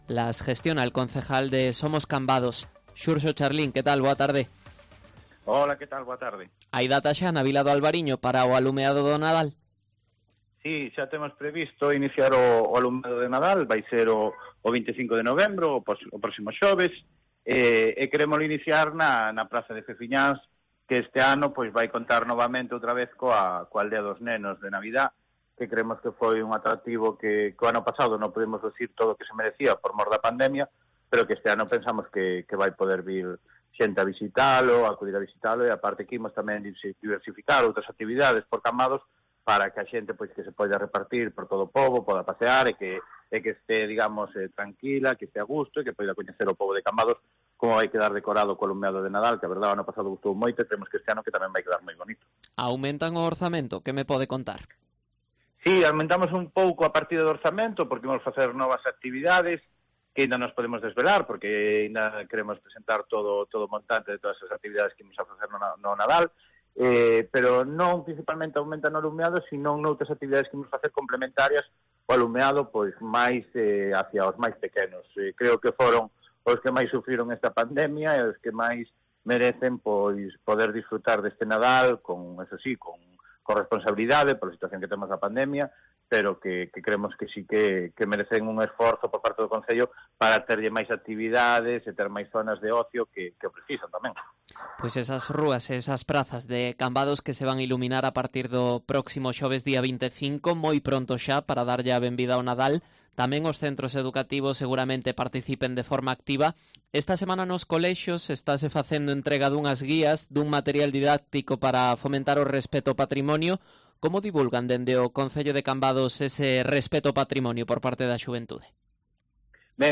Entrevista a Xurxo Charlín, edil de Somos Cambados